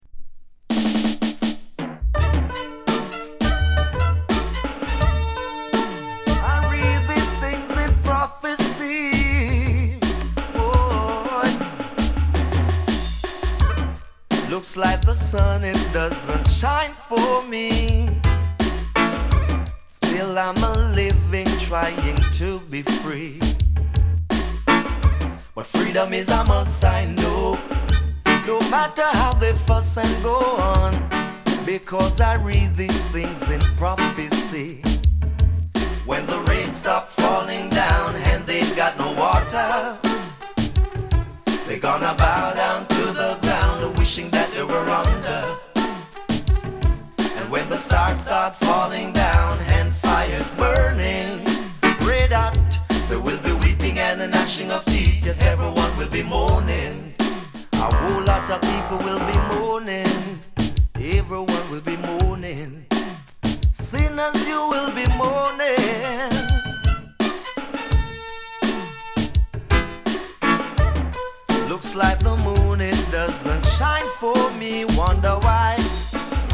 高品質 90s 唄もの *